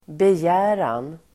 Uttal: [bej'ä:ran]